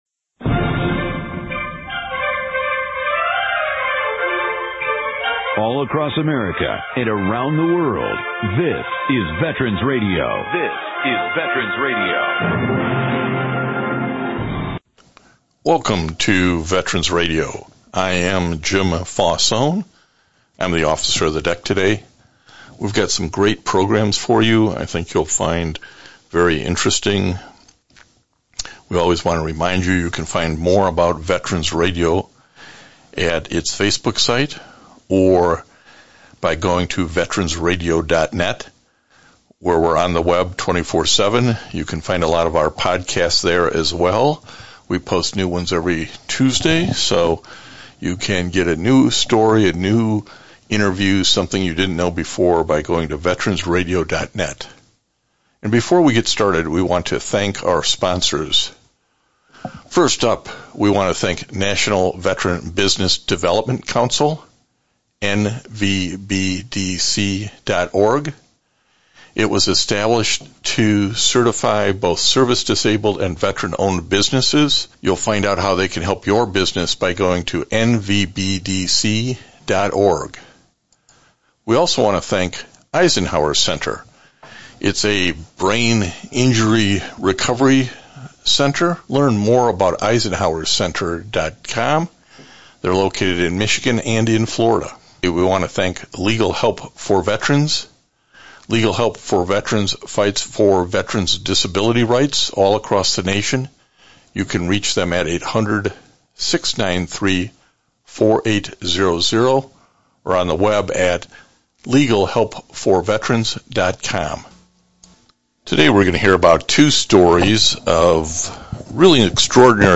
September 12, 2021 This week’s one hour radio broadcast is pre-recorded.